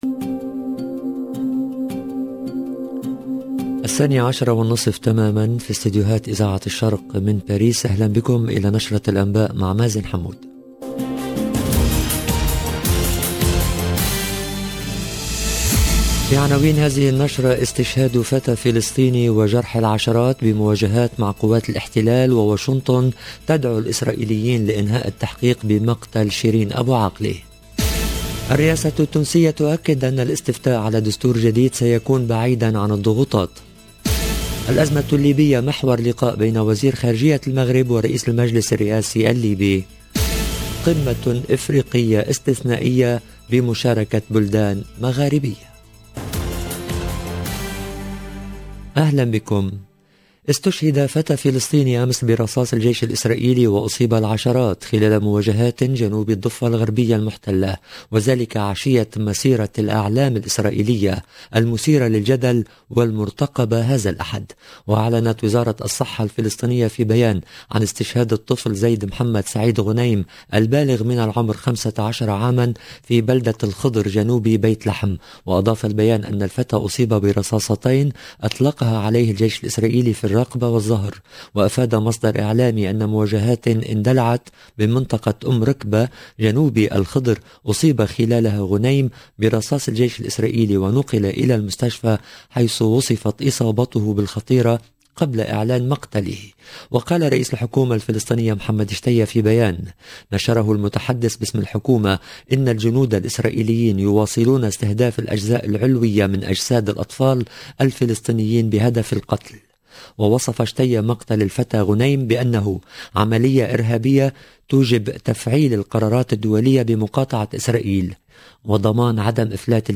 LE JOURNAL EN LANGUE ARABE DE MIDI 30 DU 28/05/22